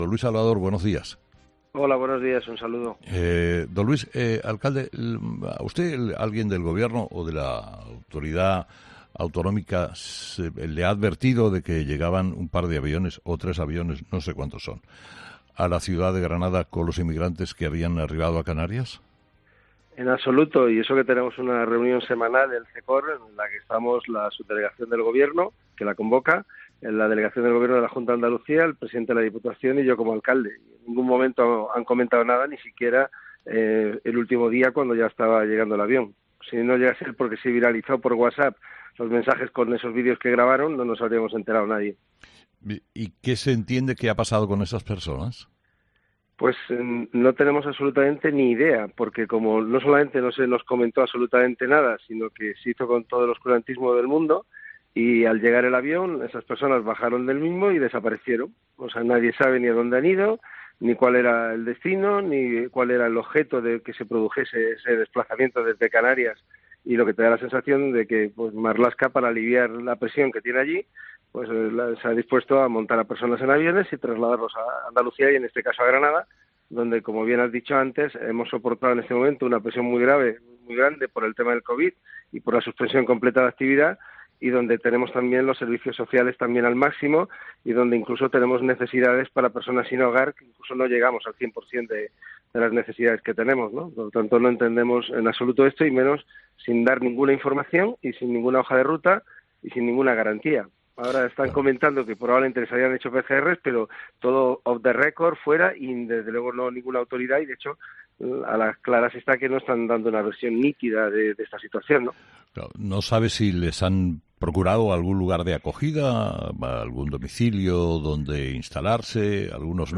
Luis Salvador, alcalde de Granada, ha sido entrevistado este miércoles en 'Herrera en COPE' después de la llegada, a lo largo de la última semana, de alrededor de 200 inmigrantes procedentes de las islas Canarias, donde se encontraban en campamentos improvisados y hoteles reconvertidos en centros de internamiento.